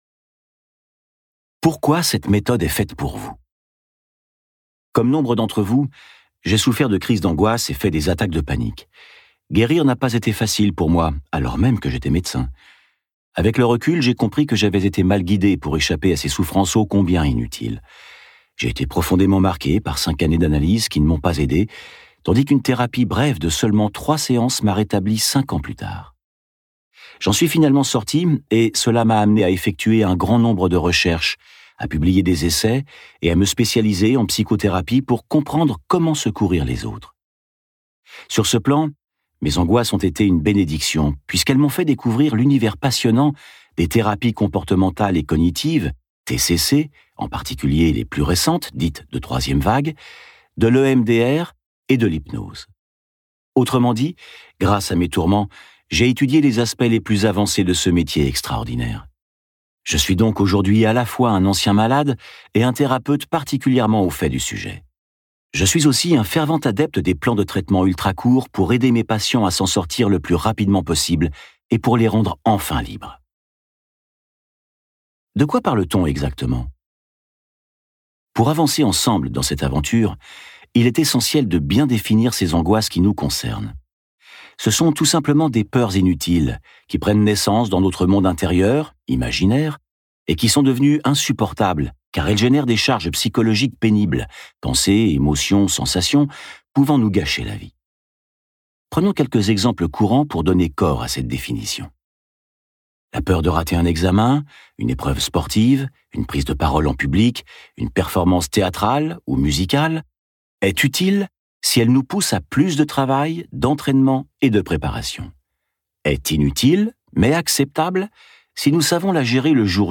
Ce livre audio vous permettra d'appréhender, en seulement 6 séances, les mécanismes de la peur et son fonctionnement.